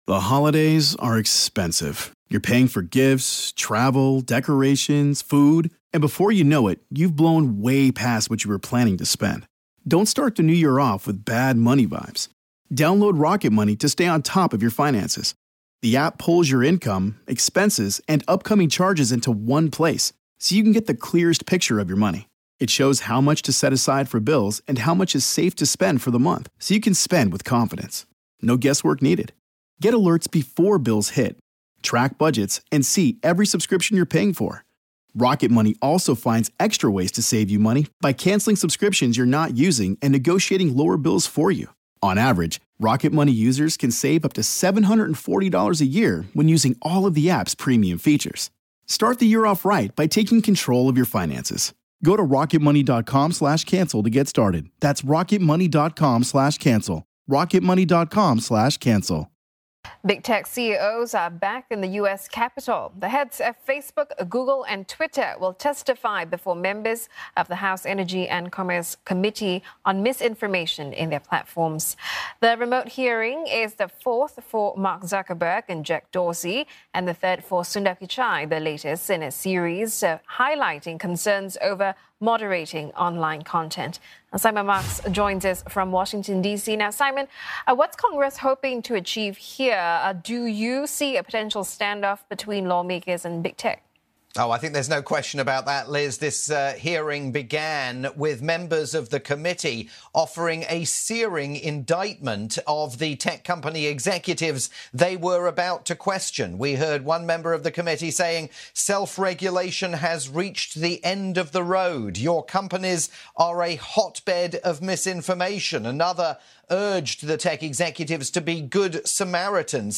live report